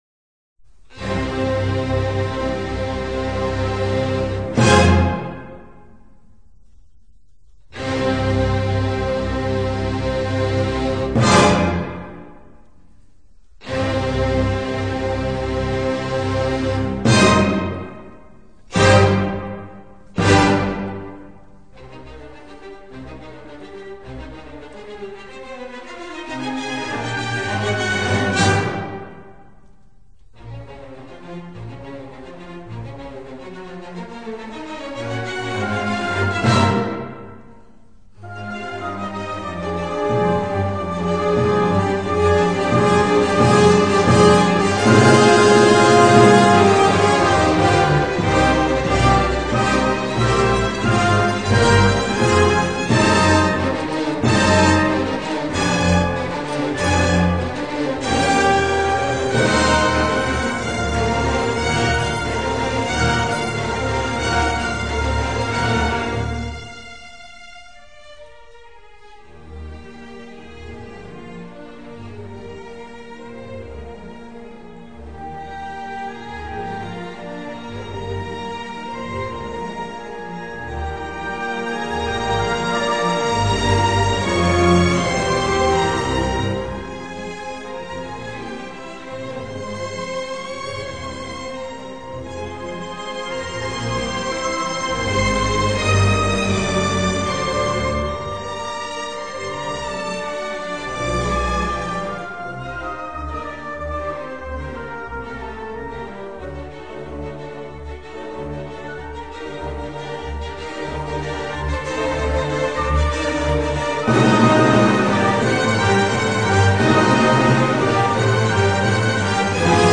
Noble, hautaine parfois, emportée souvent, avec de vibrants appels de cuivres, mais toujours droite comme un " i " !
Philharmonic Symphony Orchestra. London Philharmonic Orchestra*.
A furiously ecstatic Beethoven.